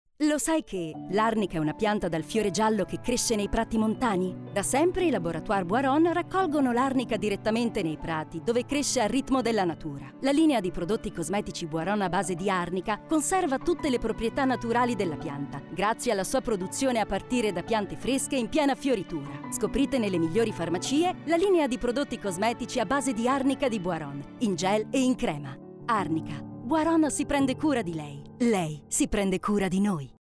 Commerciale, Naturelle, Amicale, Chaude, Douce
Corporate
MacBook Pro, Neumann Tim 103, FocusRite.